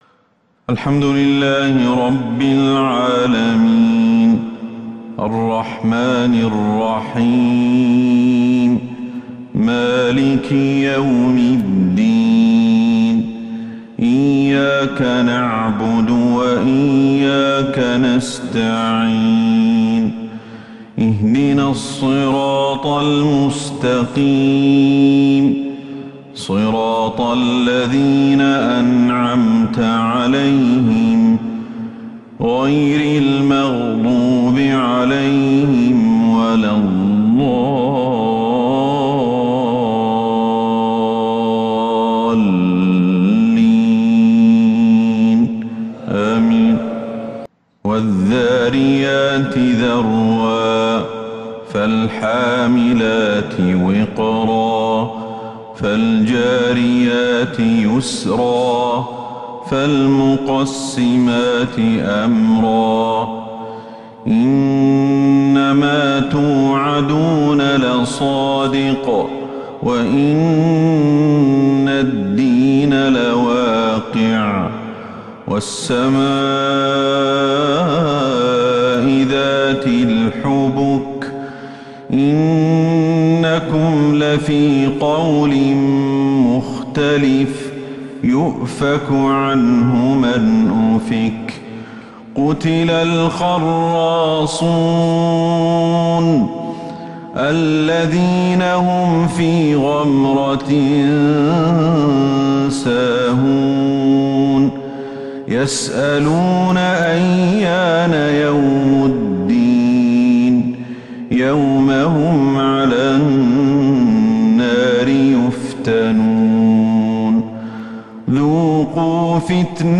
عشاء الأربعاء 9 ذو القعدة 1443هـ فواتح سورة {الذاريات} > 1443 هـ > الفروض